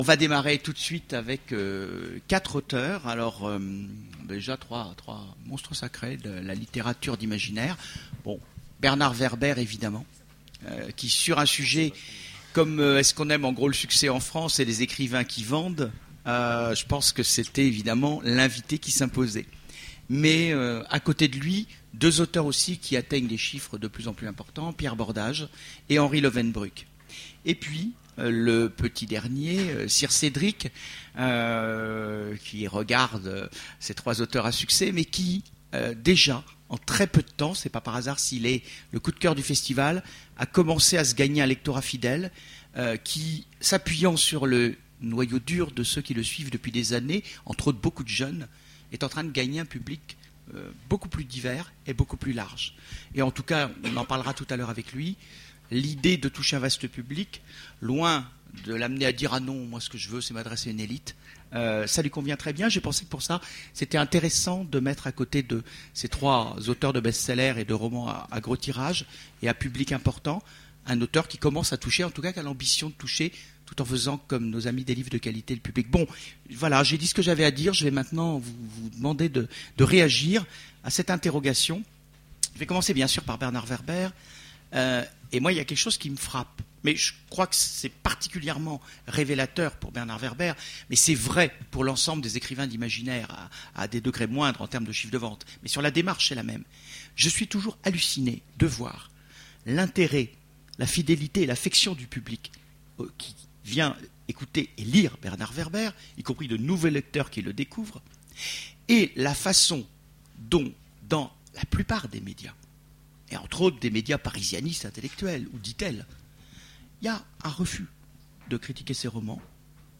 Voici l'enregistrement de la conférence Écrire pour le grand public… Est-ce mal vu en France ? aux Imaginales 2010